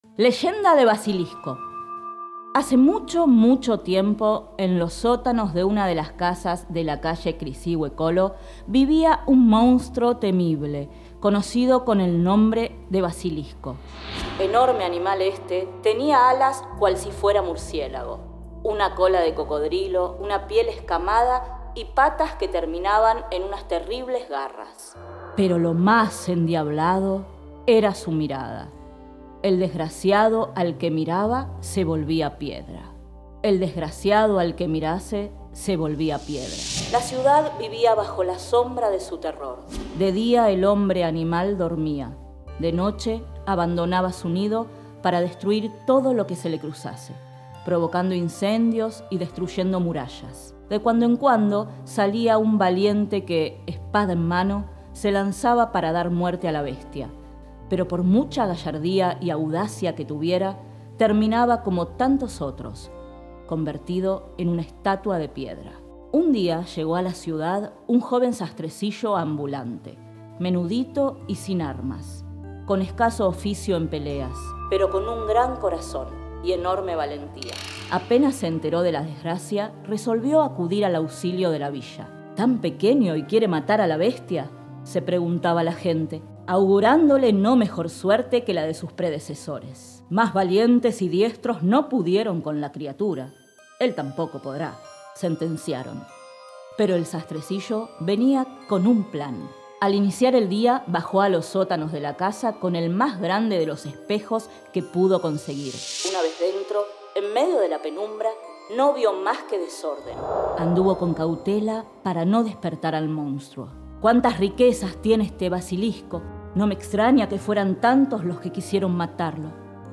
Cuentos contados: El Basilisco leyenda
leyenda de polonia basilisco final_0.mp3